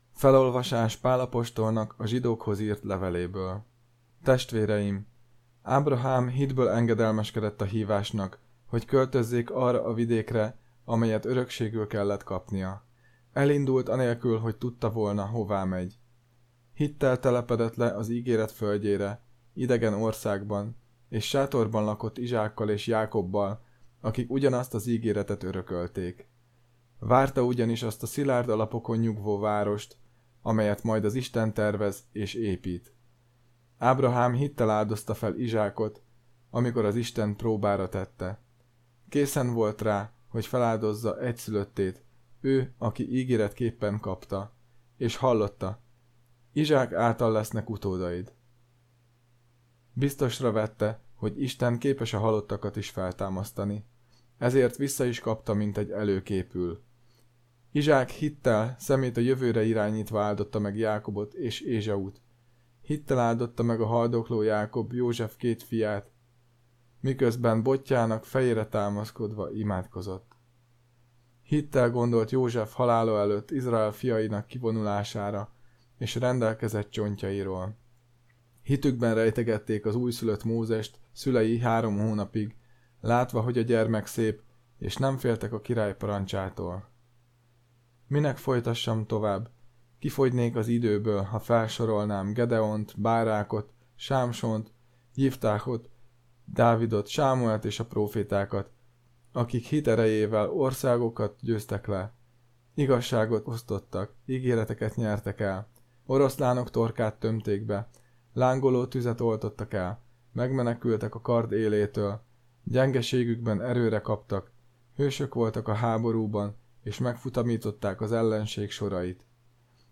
„Természet és Törvény” – Szent Atyák Vasárnapja – 2024. december 22. – Szent György Nagyvértanú templom – Szeged
Apostoli olvasmány: